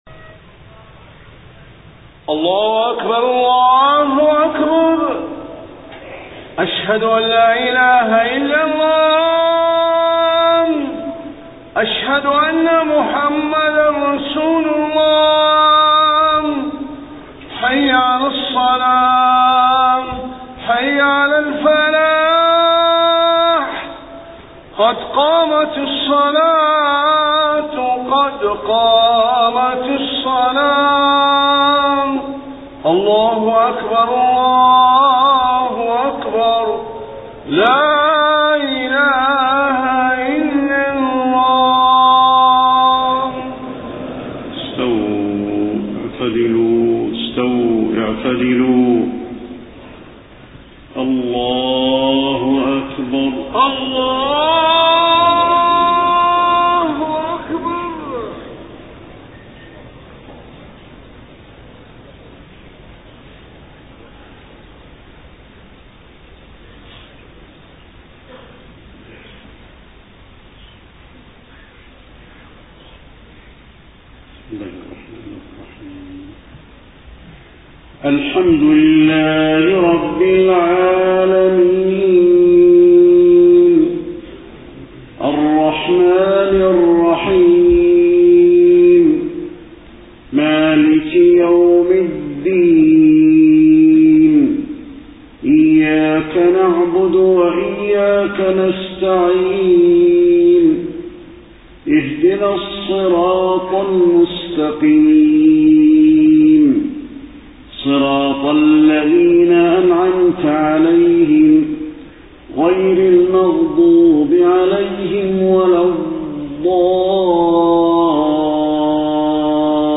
صلاة العشاء 25 صفر 1431هـ خواتيم سورة الدخان 40-59 > 1431 🕌 > الفروض - تلاوات الحرمين